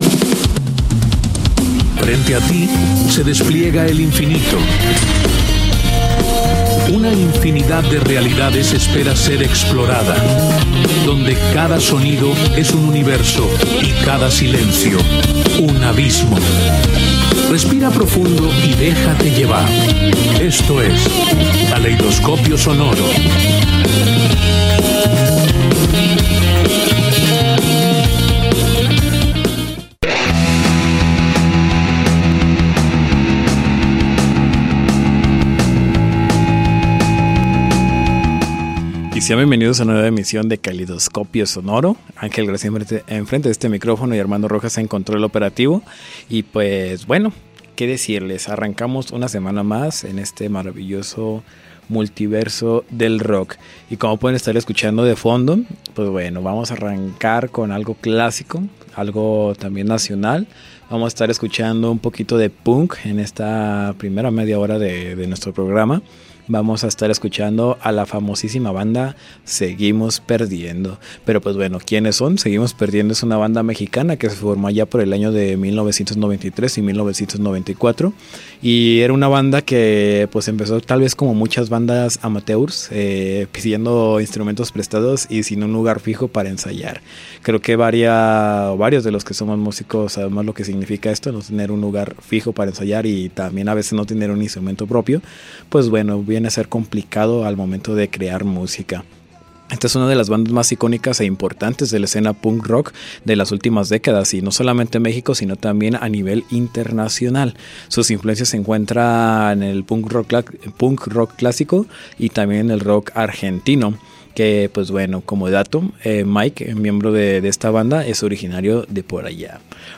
Somos un programa dedicado a descubrir y recordar a aquellas bandas de Rock en español que dejaron huella en la historia de este género, y también a aquellas que comienzan a dejar huella. Buscamos esos covers de temas de otros géneros, traídos al mundo del rock. También te contamos aquellas historias detrás de cómo se compuso, como se grabó o cuál fue la anécdota de aquellas canciones que todos conocemos.